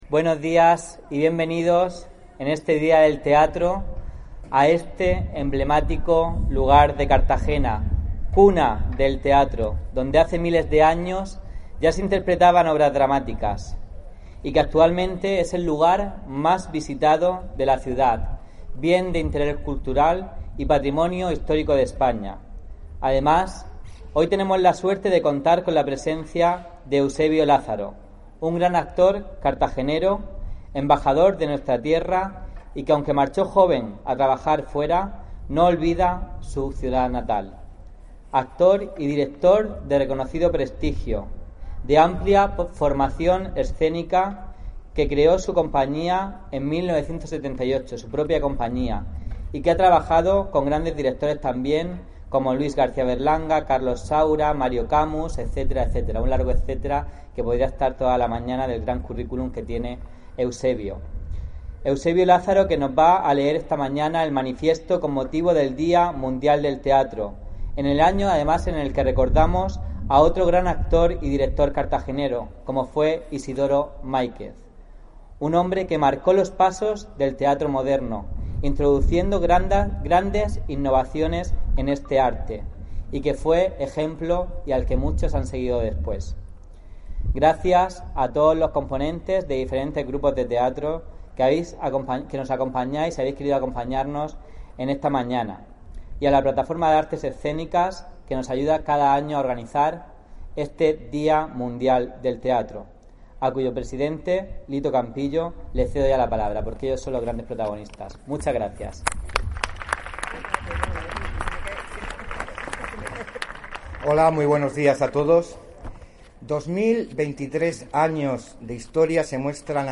El acto se ha celebrado el Martes Santo en el Teatro Romano de Cartagena
Audio: Lectura del manifiesto del D�a Mundial del Teatro (MP3 - 8,01 MB)